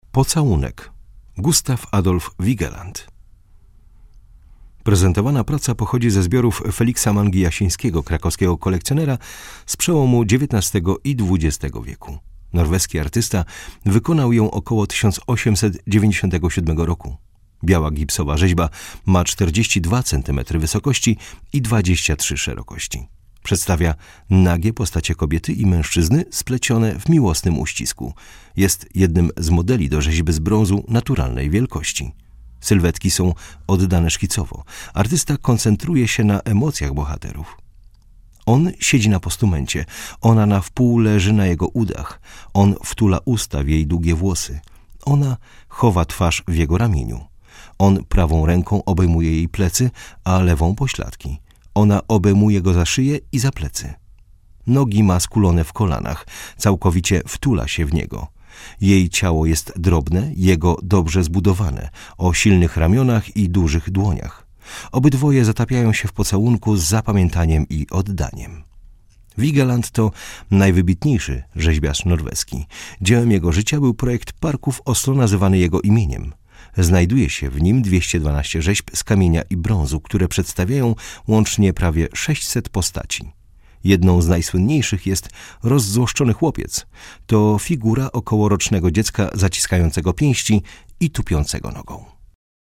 Audiodeskrypcja - EUROPEUM